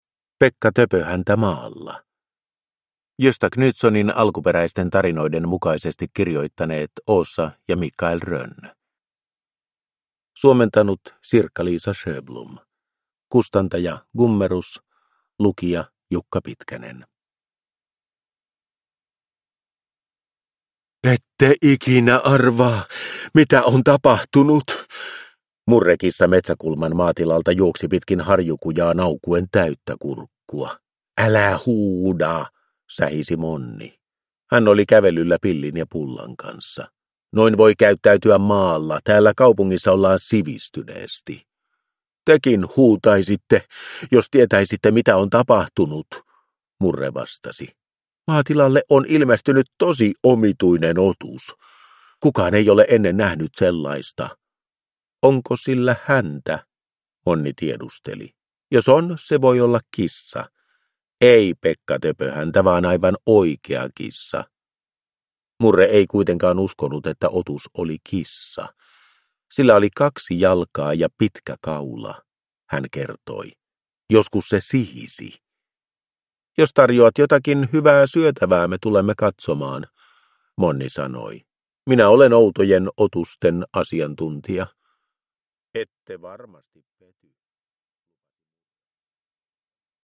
Pekka Töpöhäntä maalla – Ljudbok